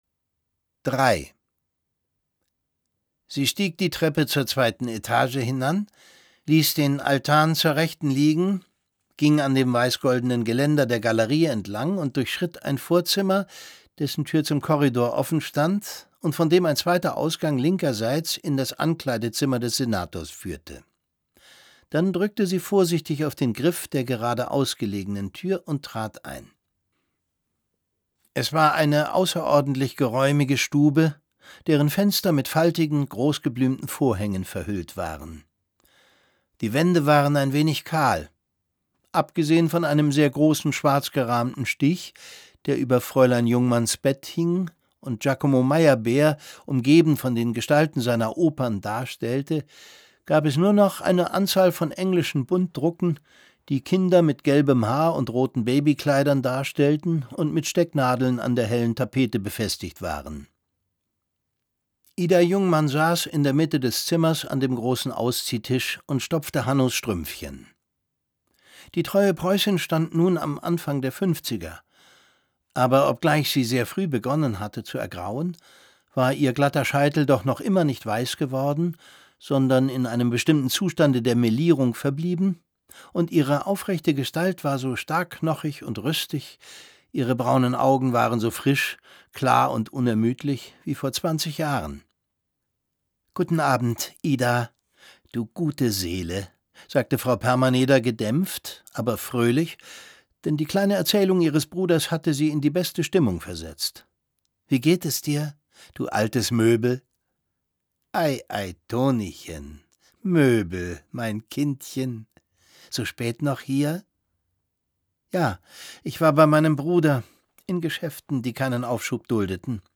Es liest Thomas Sarbacher.